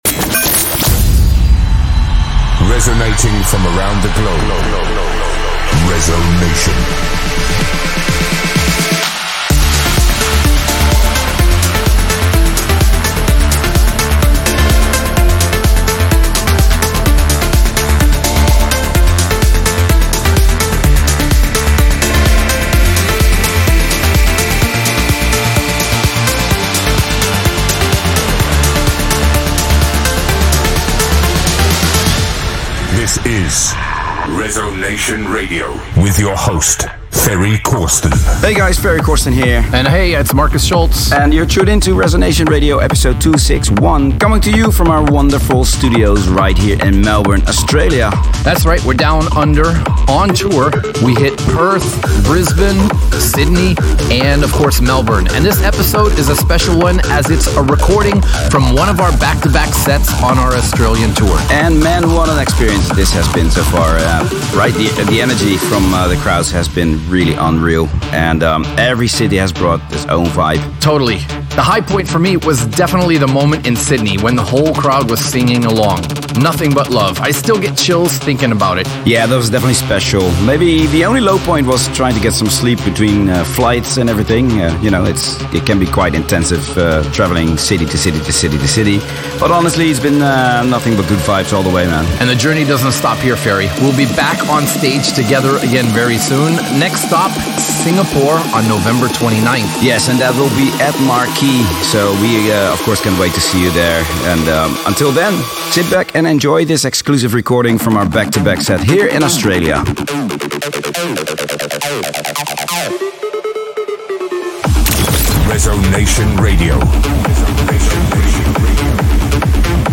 Also find other EDM Livesets, DJ Mixes and Radio Show
a unique B2B recorded from Melbourne Pavilion, Australia